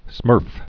(smûrf)